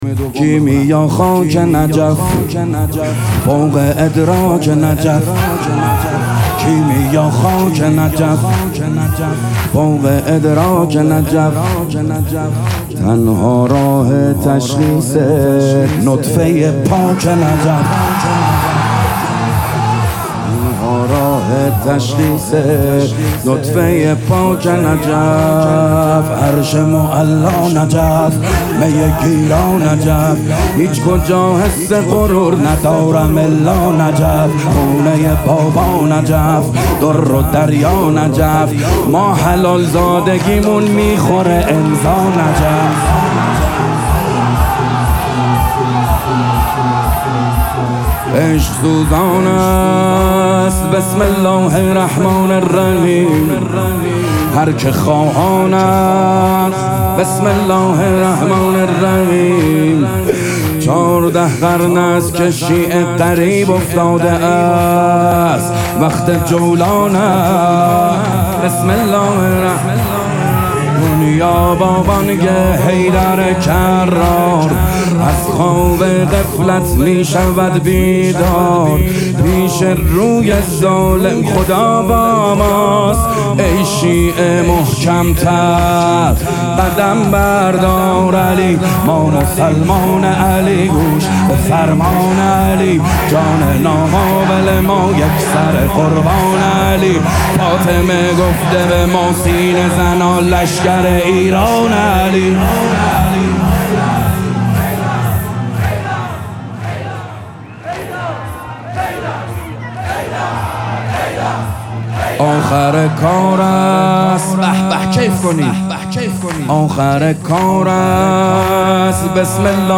فاطمیه دوم 1403